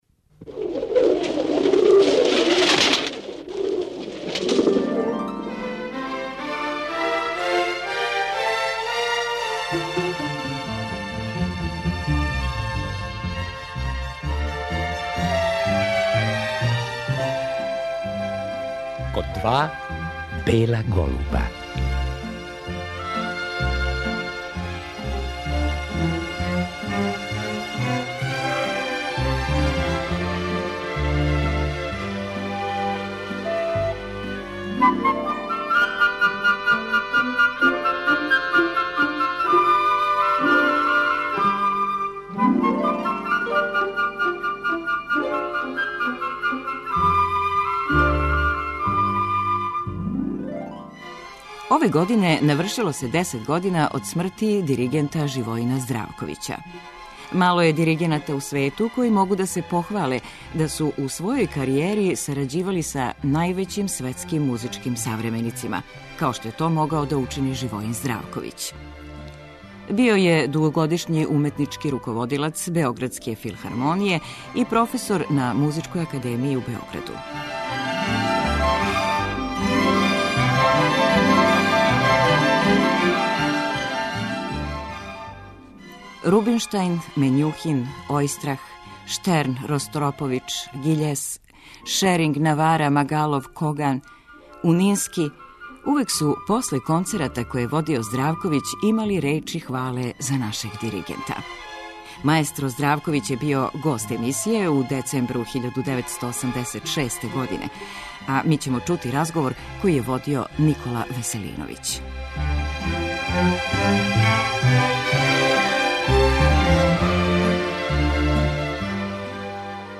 Ми ћемо чути разговор